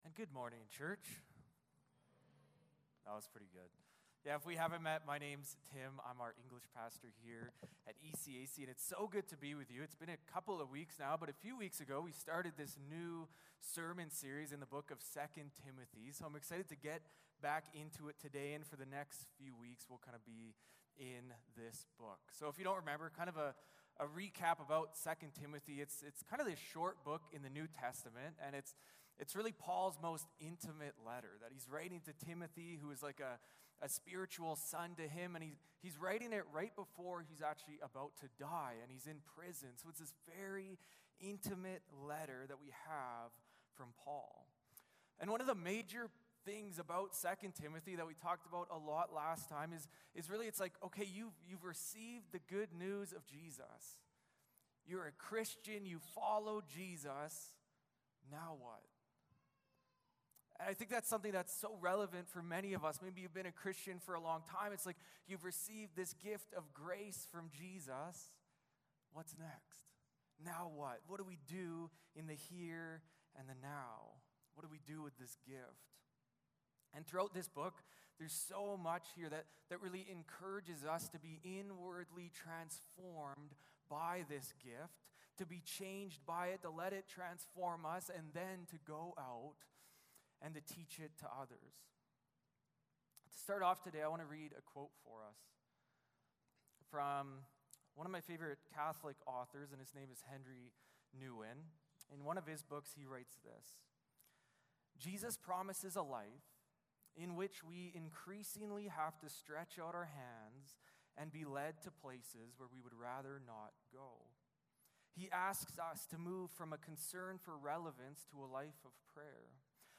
1-13 Service Type: Sunday Morning Service Passage